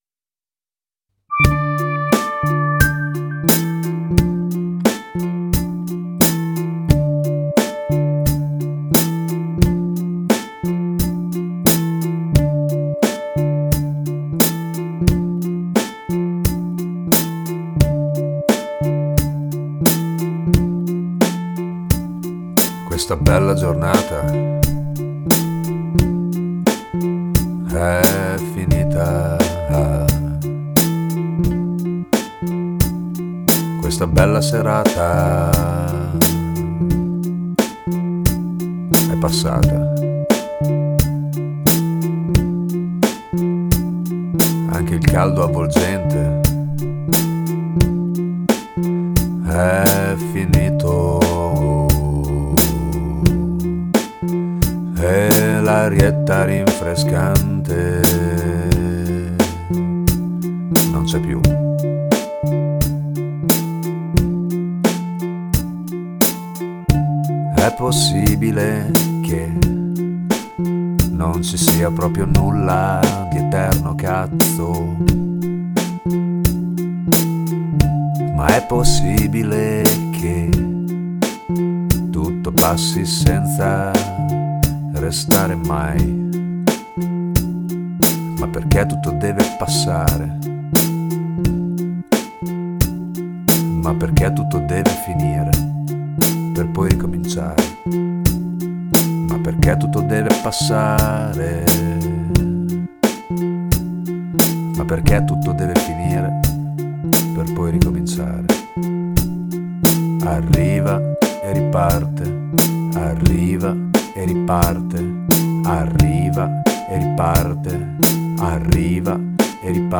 Luogo esecuzioneCasa mia
GenerePop